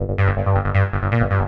hous-tec / 160bpm / bass
rubber-4.wav